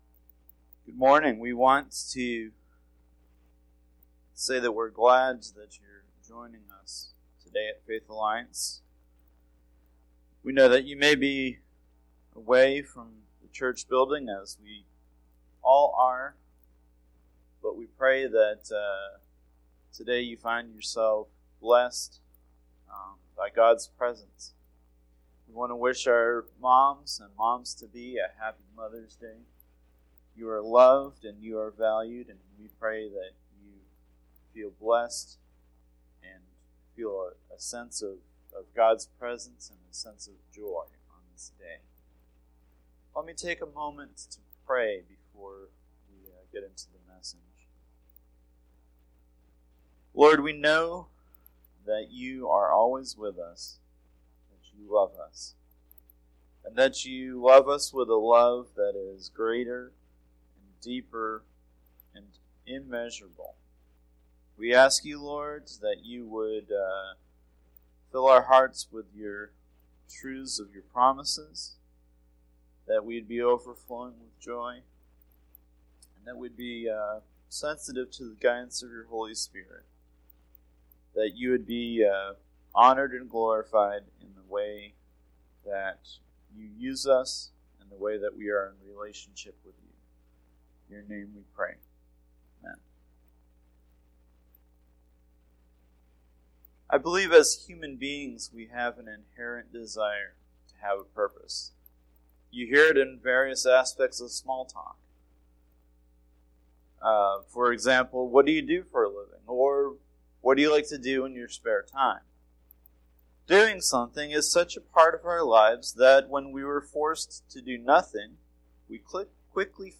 Sermon-5-10-20.mp3